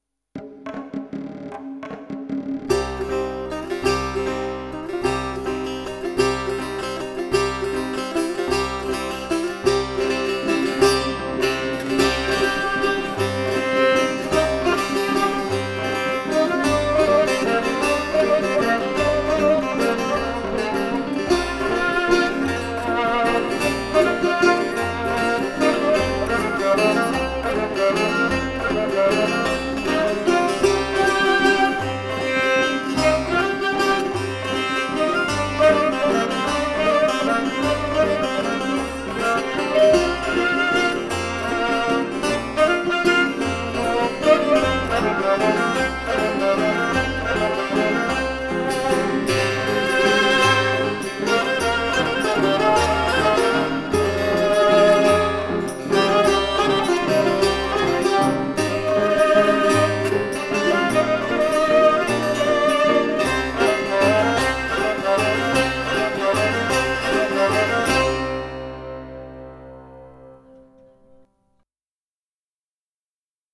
Persion Version